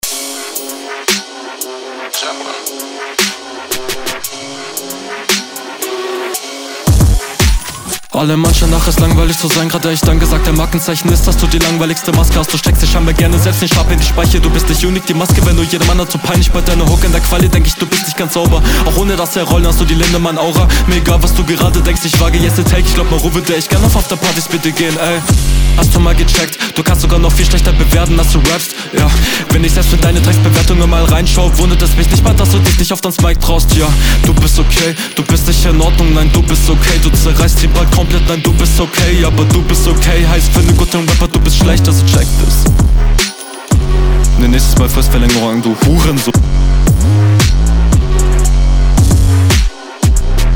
flow ist teilweise echt gehetzt und das wirkt alles andere als safe, mische mag ich …